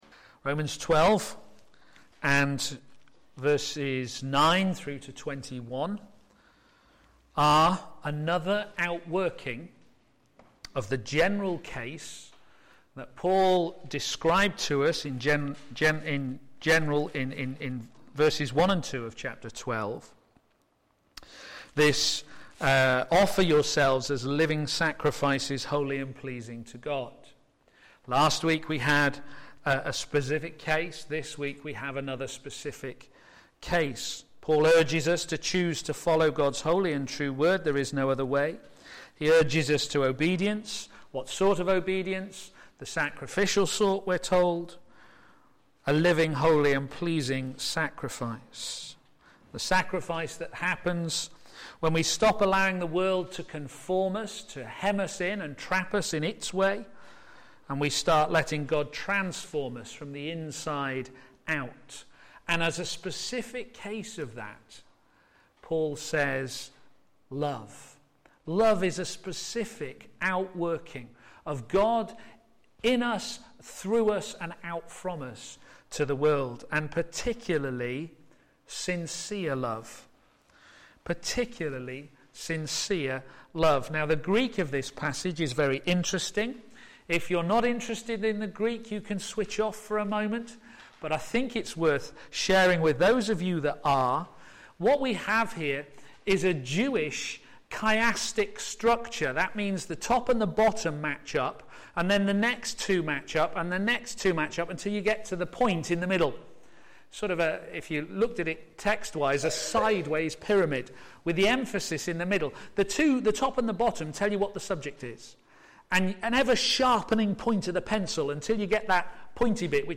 p.m. Service
Loving the Unlovable Sermon